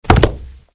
Toon_bodyfall_synergy.ogg